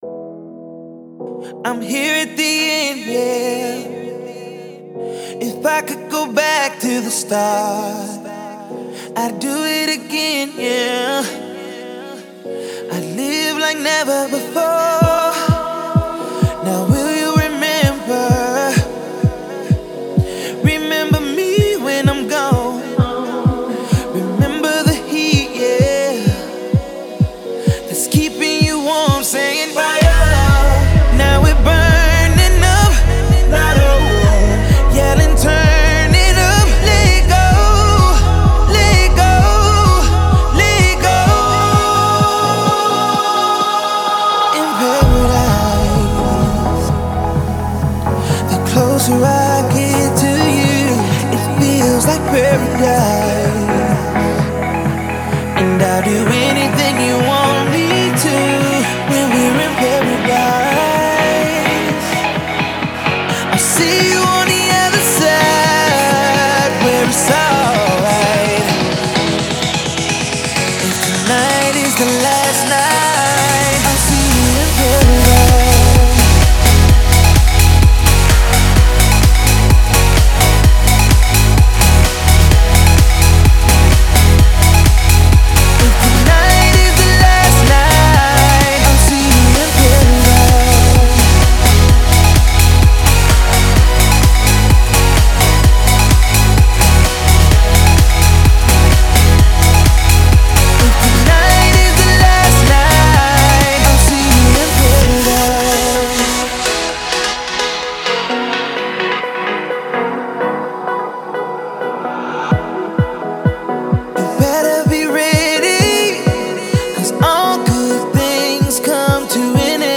Главная » Файлы » Клубная Музыка Категория